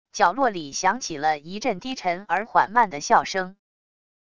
角落里响起了一阵低沉而缓慢的笑声wav音频